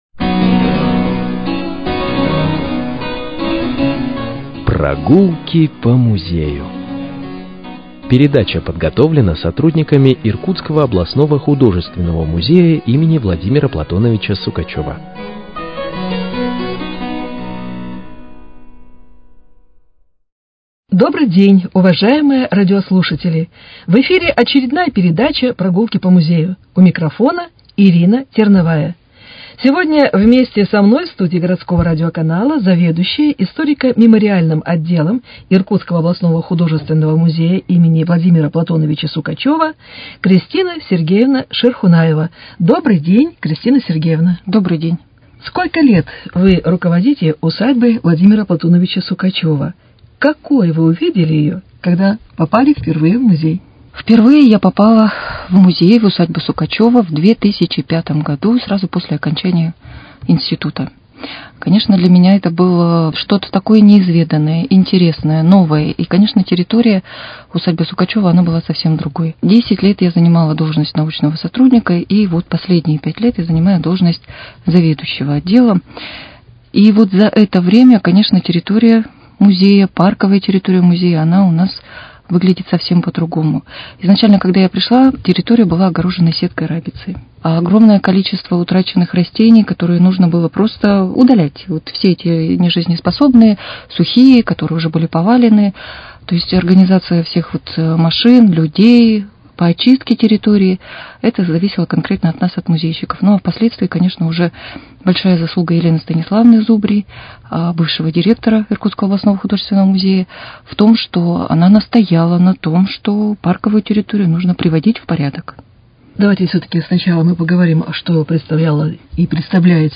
Вашему вниманию беседа
Музыкальное украшение передачи – романс «В нашем старом саду»